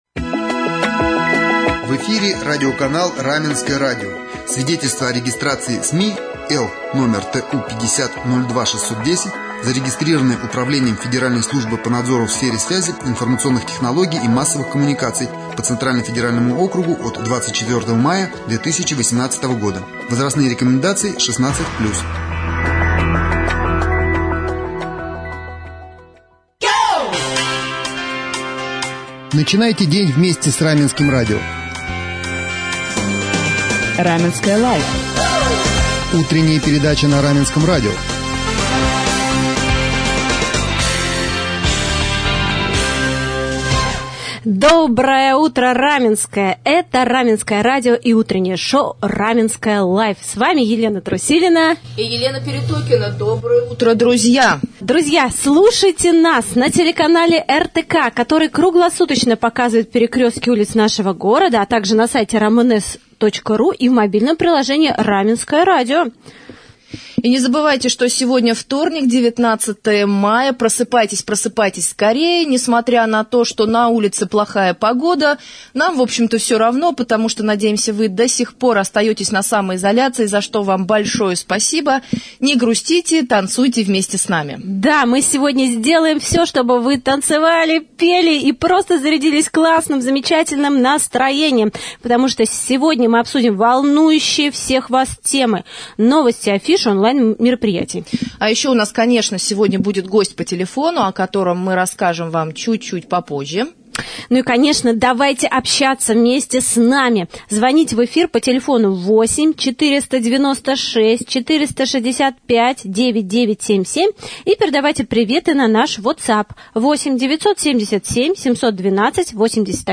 Однако некоторые из-за пандемии лишились работы, поэтому мы решили обсудить новые меры поддержки безработных в утренней передаче «Раменское life».